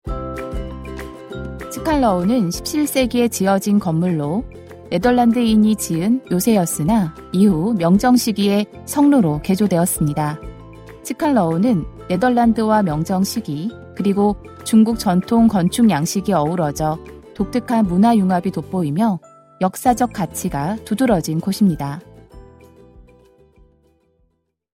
한국어 음성 안내